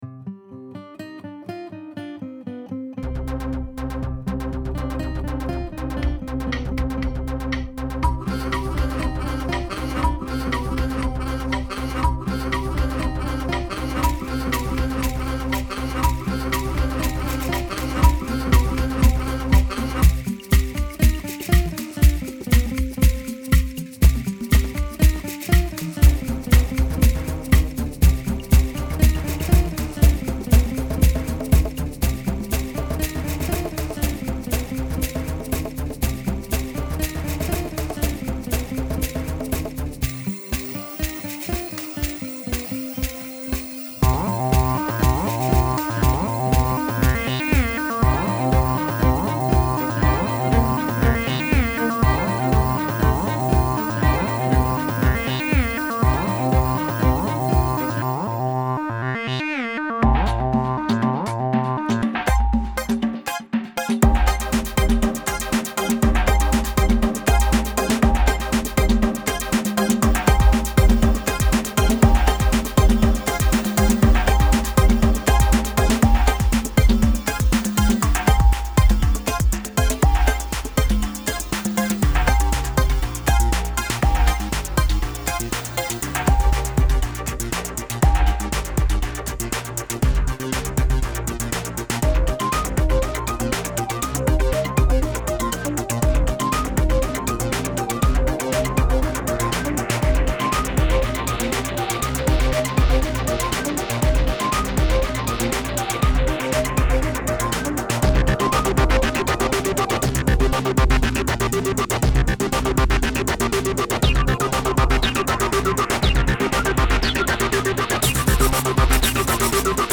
weeping woman